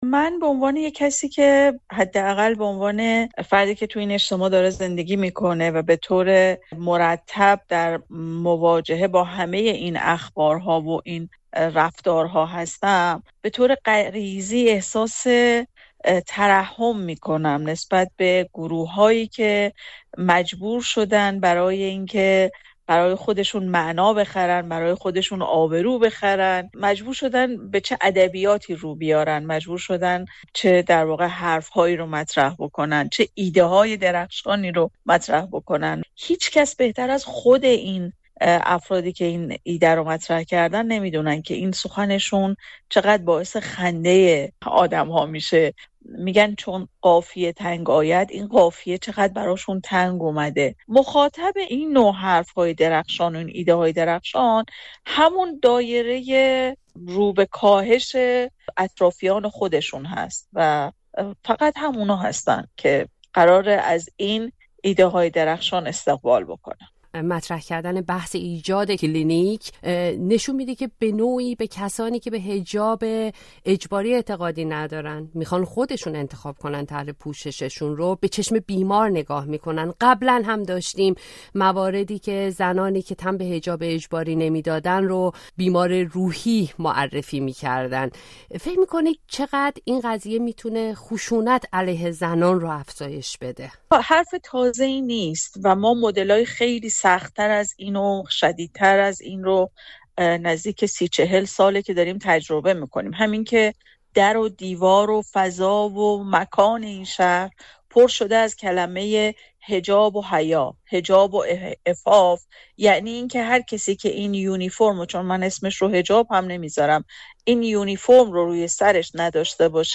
«کلینیک ترک بی‌حجابی» در گفت‌وگو